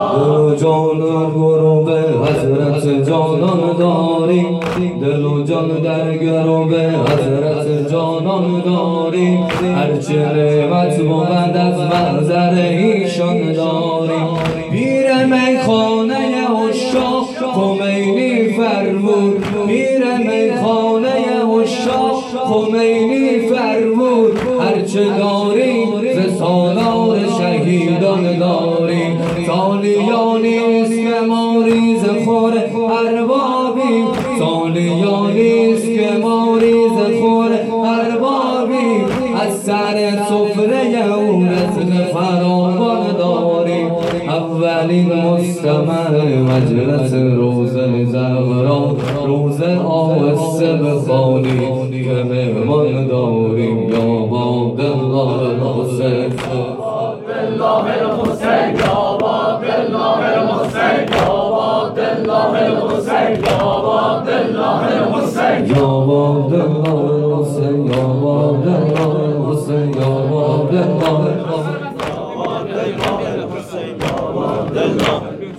شب اول محرم ۹۷ هیئت صادقیون(ع)